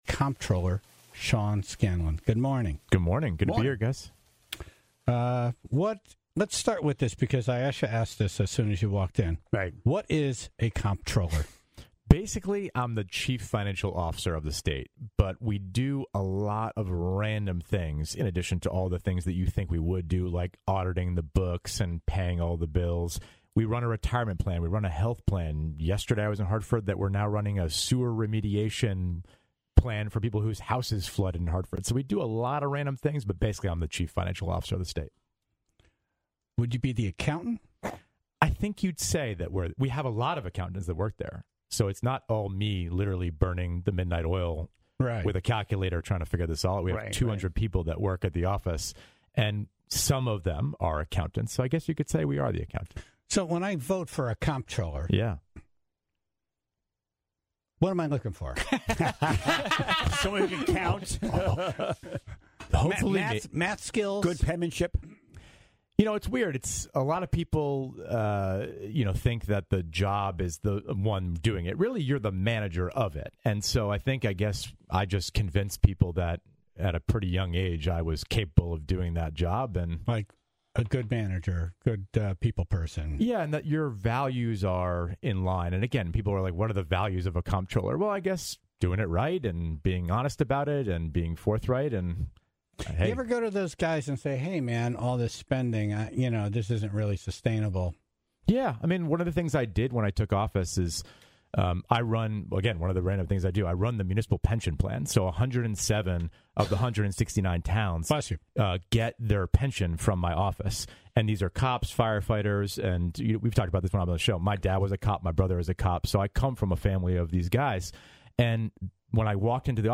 CT State Comptroller Sean Scanlon was in studio to explain what his job is, the way he recently saved towns over $740 million for the next 30 years, and answered a lot of questions about the boy band he was in with Nick Fradiani in high school.